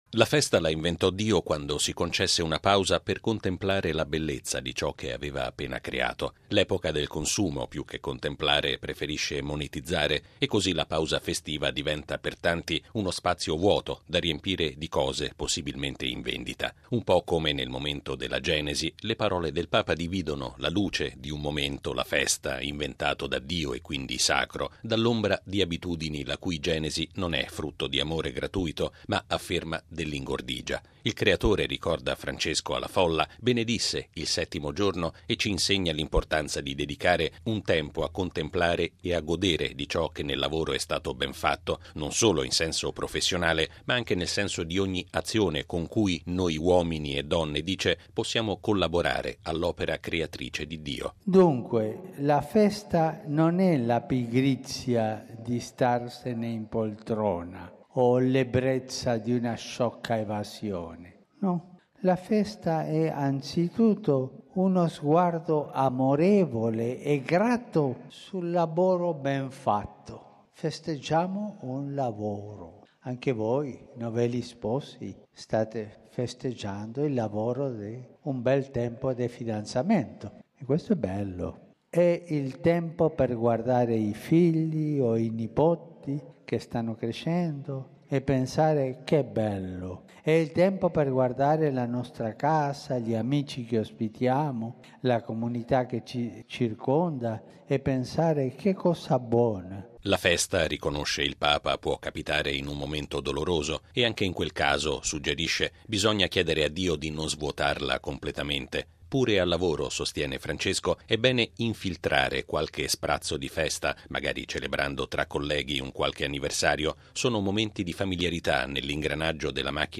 È il senso della catechesi del Papa all’udienza generale di oggi, in Aula Paolo VI, durante la quale Francesco ha invitato le famiglie a vivere la pausa domenicale con l’Eucaristia che trasfigura, ha detto, ogni momento della vita, anche doloroso.